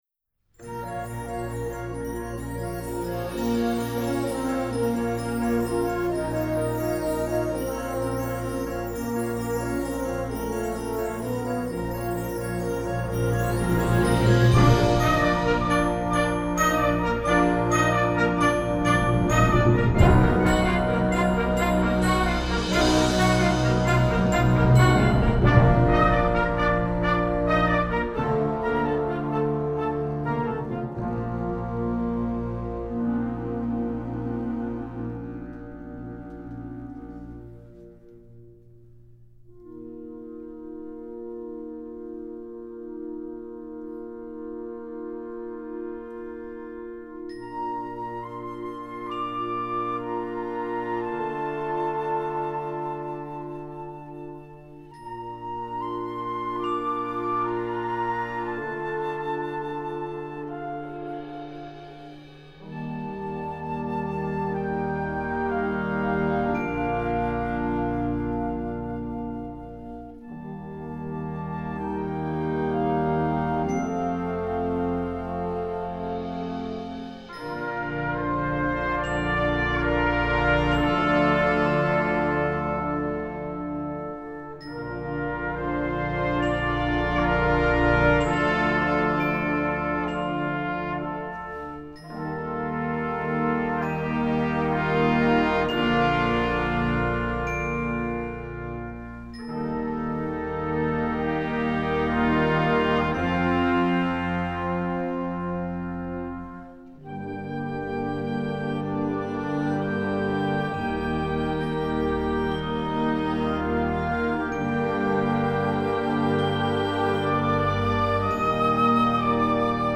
Gattung: Filmmusik für Jugendblasorchester
Besetzung: Blasorchester